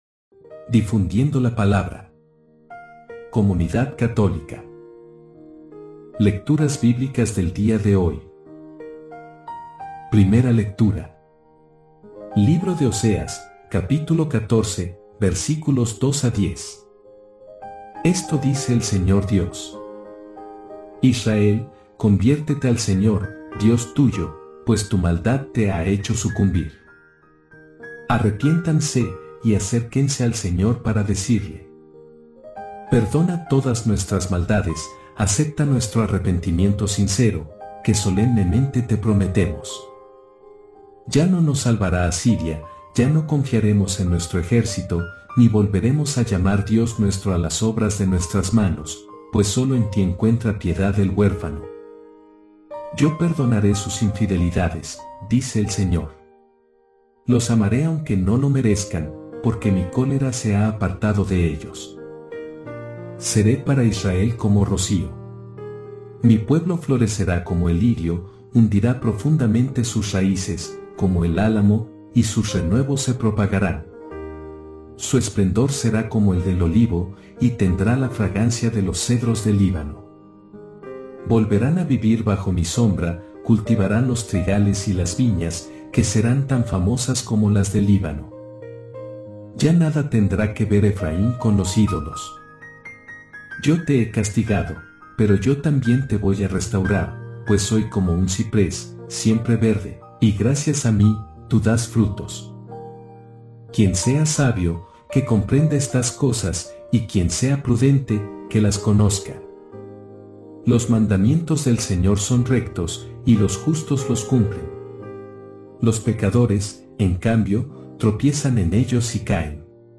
Lecturas del día
✝-Lecturas-Y-Evangelio-del-28-de-Marzo.mp3